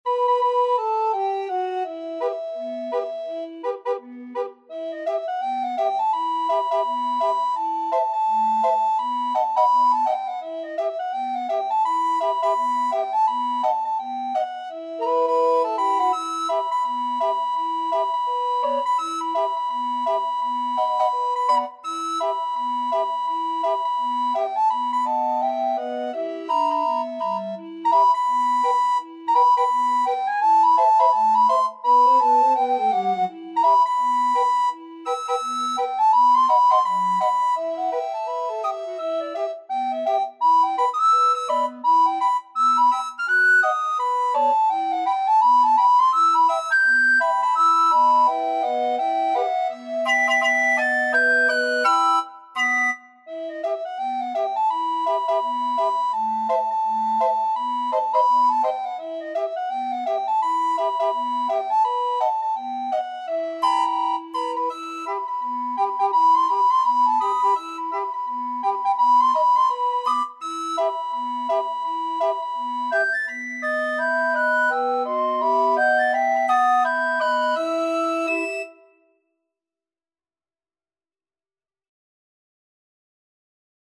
Soprano RecorderAlto RecorderTenor RecorderBass Recorder
Quick Swing = 84
2/2 (View more 2/2 Music)